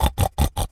pgs/Assets/Audio/Animal_Impersonations/pig_sniff_02.wav at master
pig_sniff_02.wav